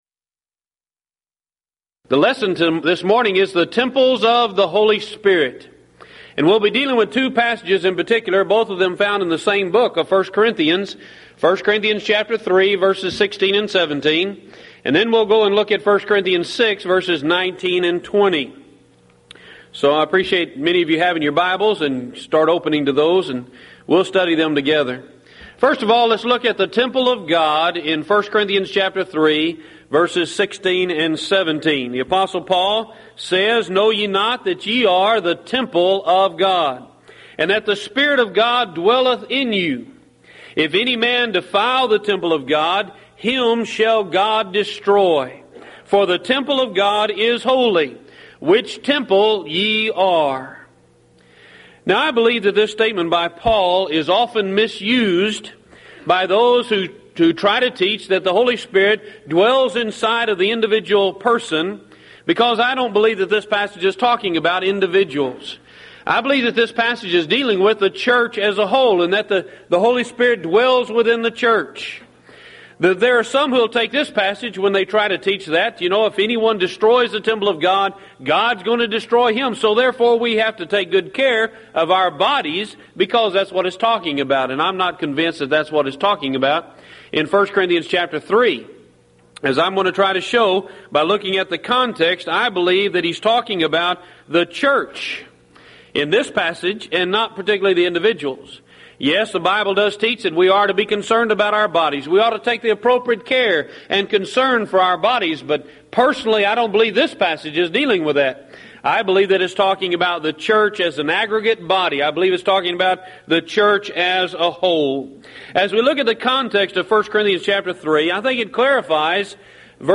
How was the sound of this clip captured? Event: 1997 Mid-West Lectures